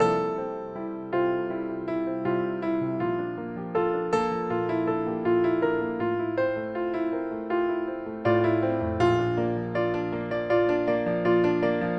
Delicate Piano 11-128kbps.mp3